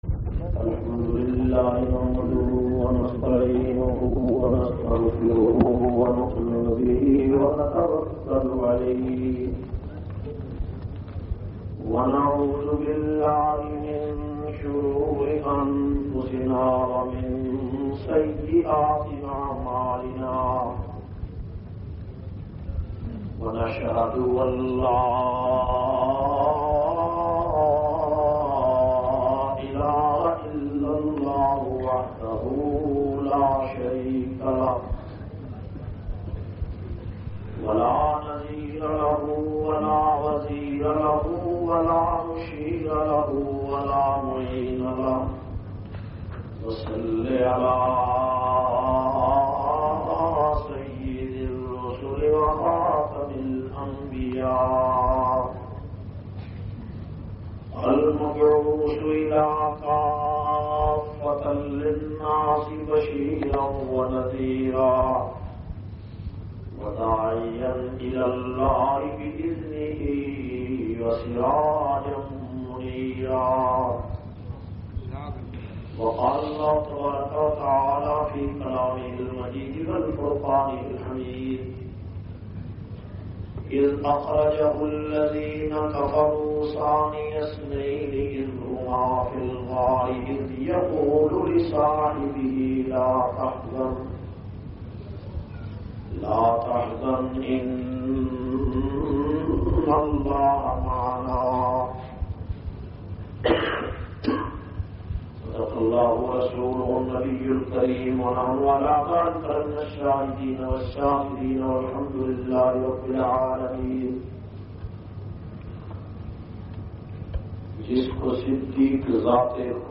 651- Shan e Siddiq e Akbar-Markazi Jama Masjid,LalaRukh,Wah Cantt,Texila-Part 01.mp3